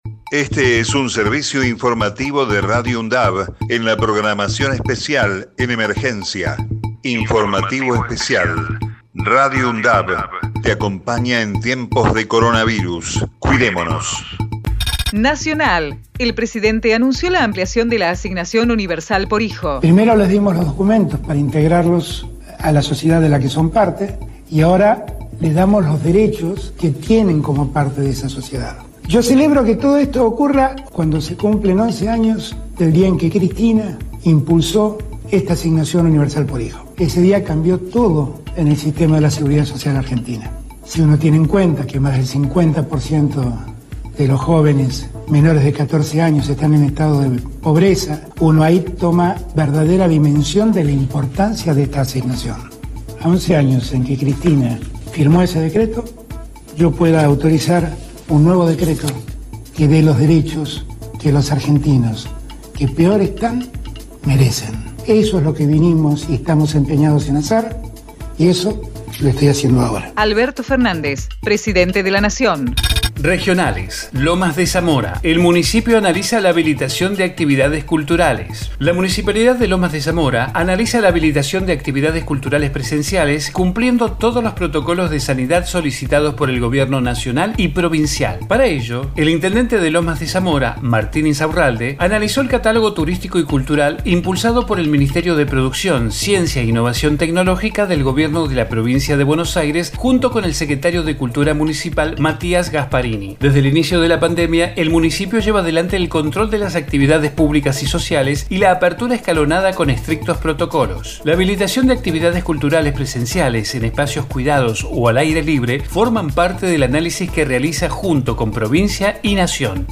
COVID-19 Informativo en emergencia 30 de octubre 2020 Texto de la nota: Este es un servicio informativo de Radio UNDAV en la programación especial en emergencia.